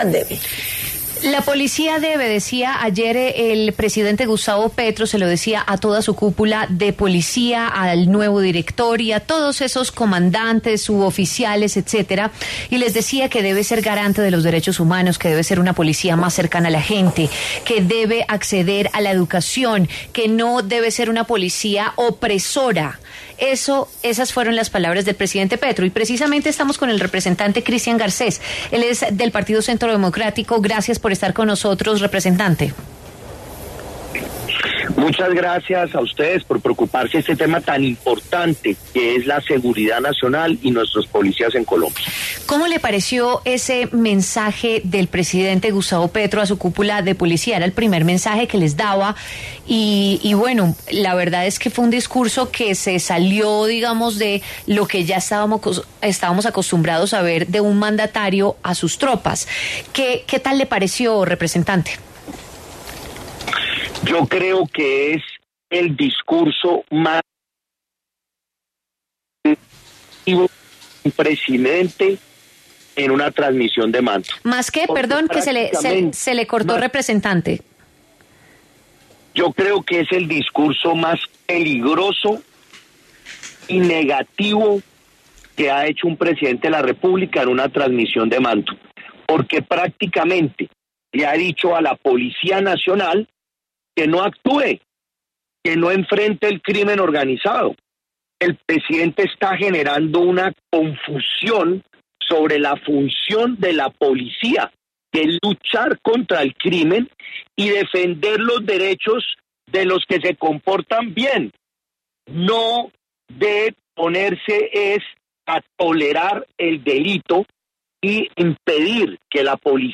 Escuche la entrevista completa al representante Christian Garcés en La W: